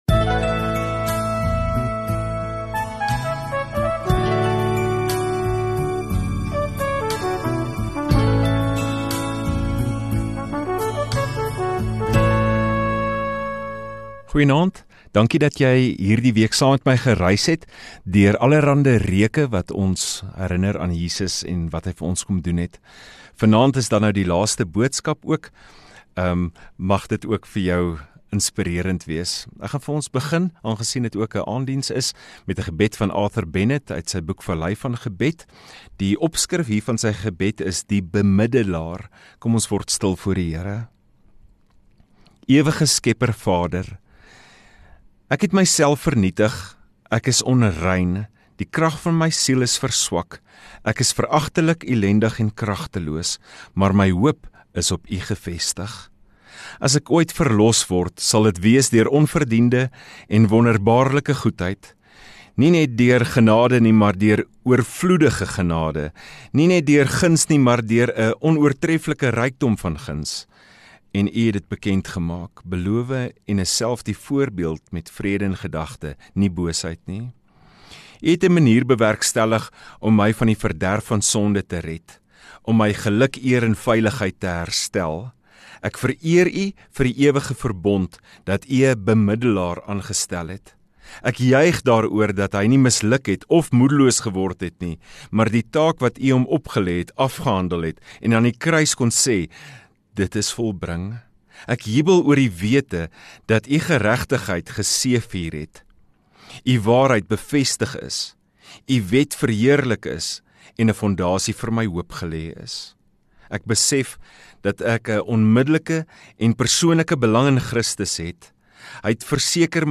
31 Mar Sondagaand Erediens